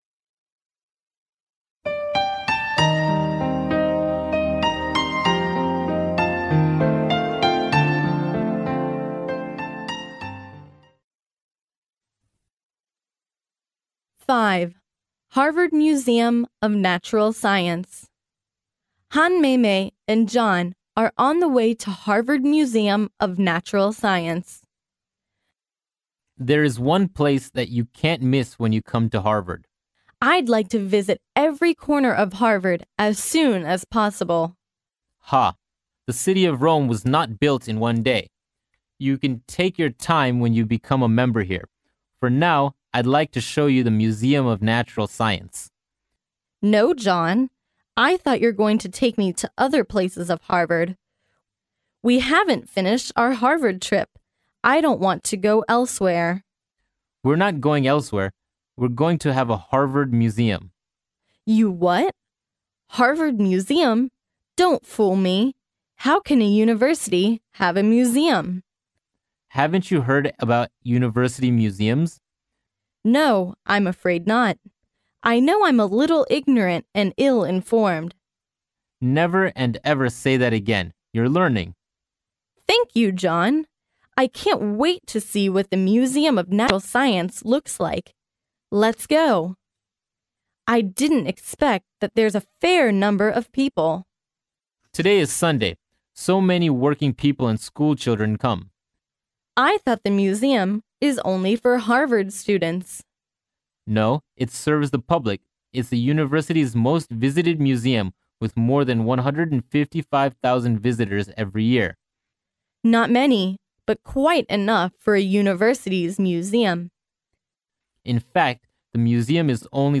哈佛大学校园英语情景对话05：自然的海洋:哈佛自然历史博物馆（mp3+中英）